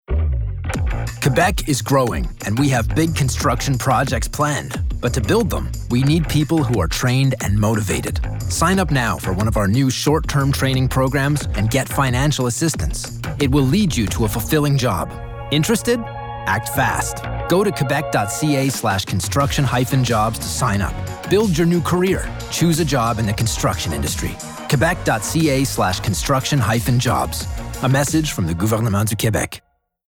Publicité (MTESS) - ANG